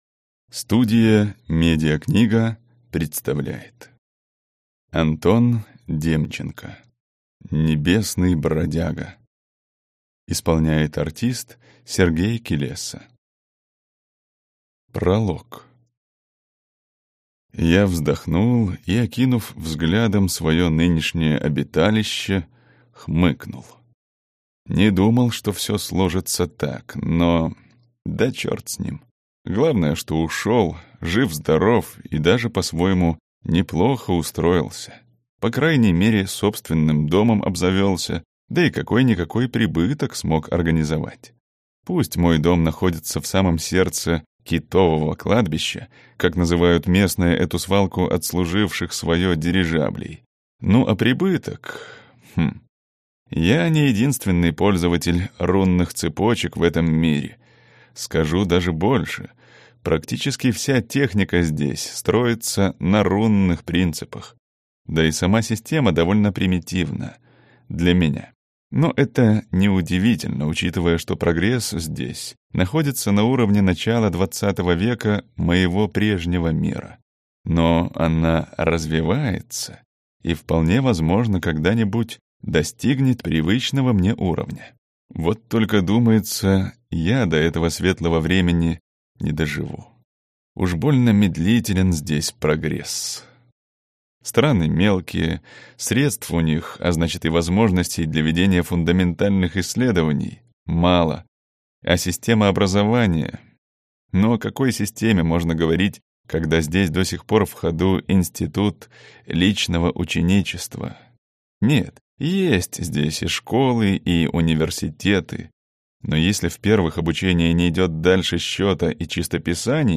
Аудиокнига Небесный бродяга | Библиотека аудиокниг